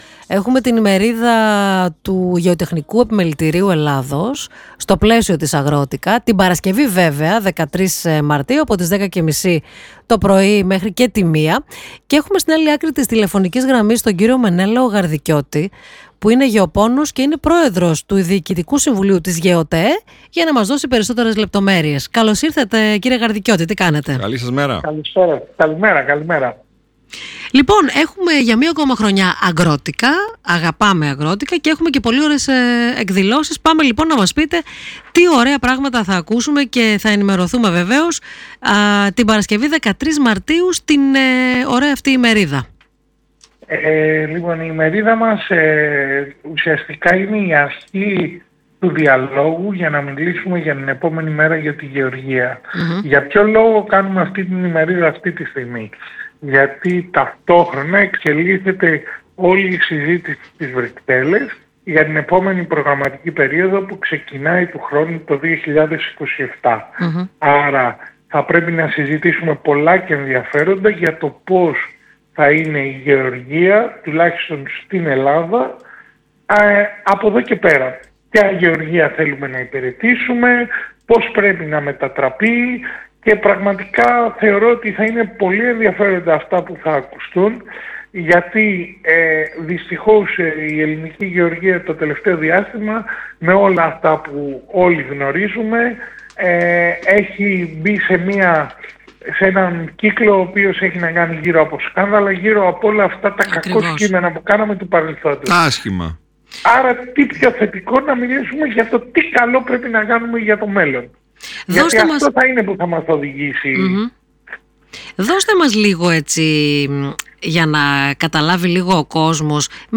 Hmerida_Agrotica_synenteyji.mp3